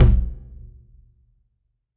9LOWTOM.wav